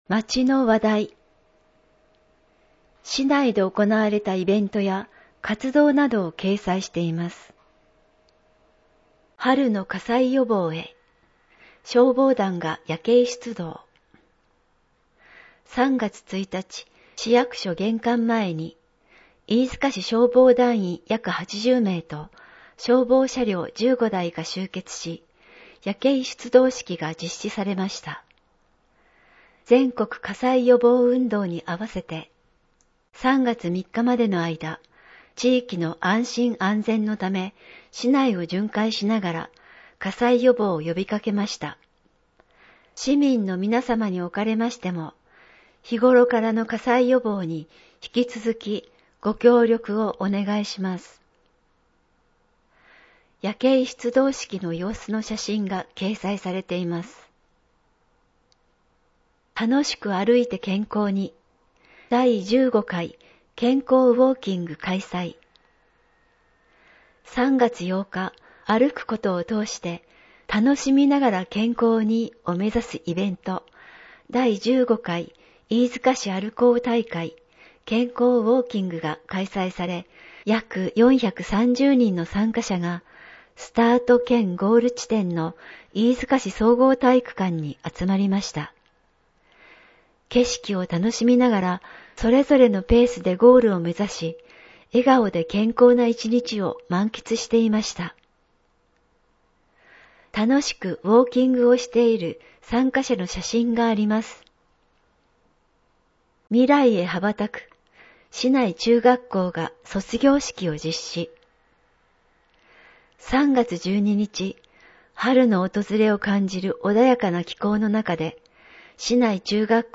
声の広報「広報いいづか」の配付
飯塚市では、ボランティア団体の皆様のご協力をいただきながら、視覚障がい者の方を対象に「広報いいづか「声の市報」」（デイジー版CD・カセットテープ）を毎月配付しています。